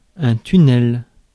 Prononciation
Prononciation France: IPA: [ty.nɛl] Le mot recherché trouvé avec ces langues de source: français Traduction Substantifs 1.